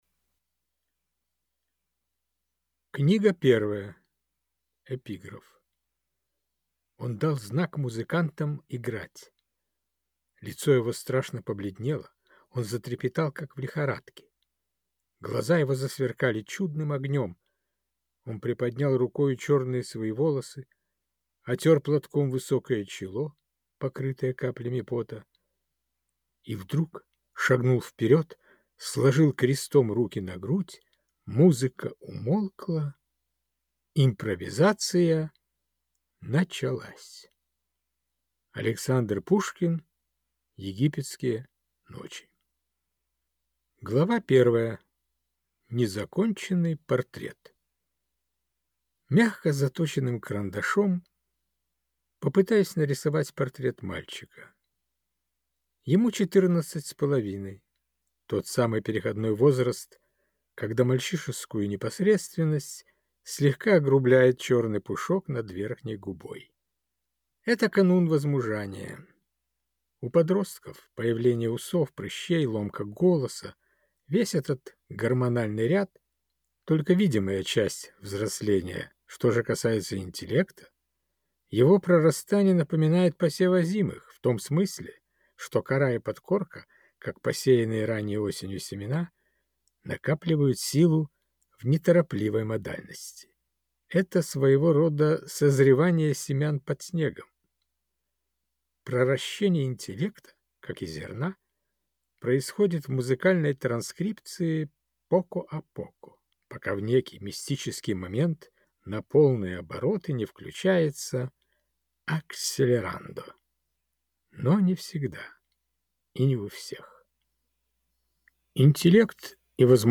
Аудиокнига Год Майских Жуков | Библиотека аудиокниг